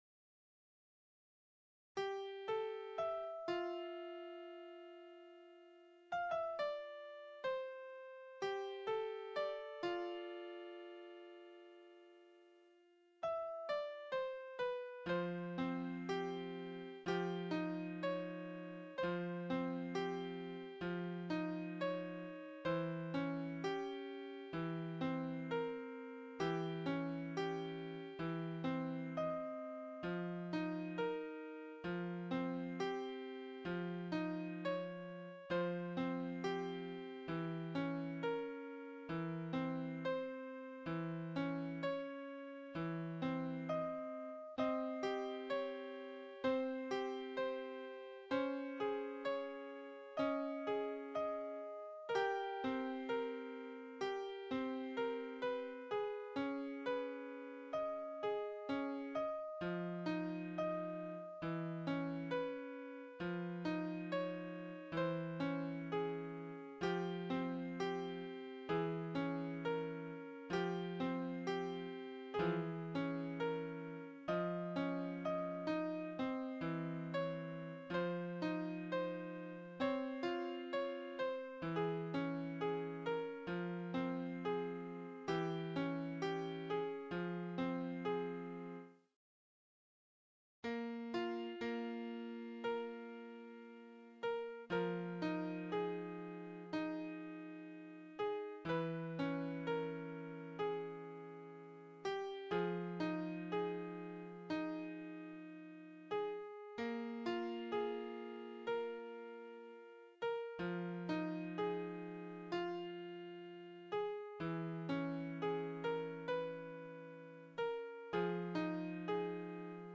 Piano Emotional Solo 139
A Solo piano tune I made for my game
pianoemo139_0.ogg